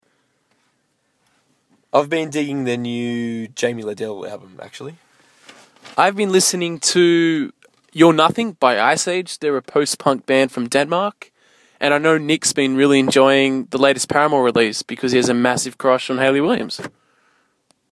SOLKYRI INTERVIEW – May 2013